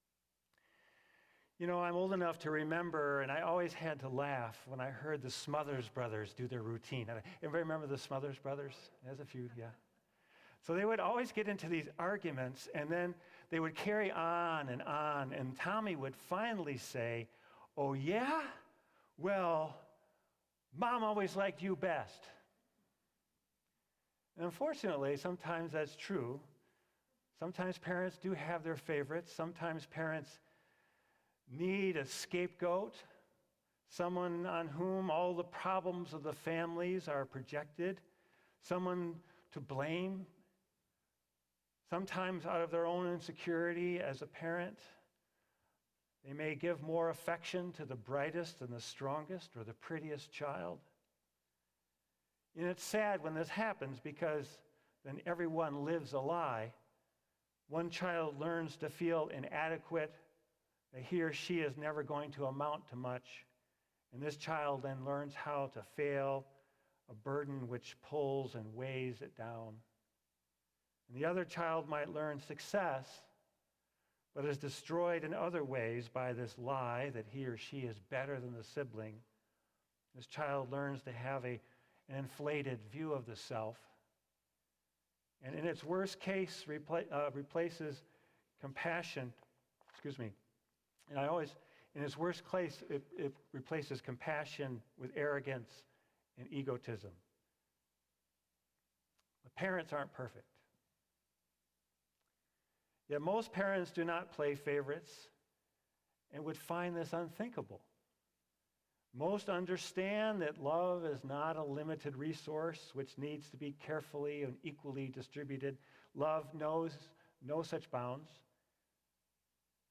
sermon-5-22-22.mp3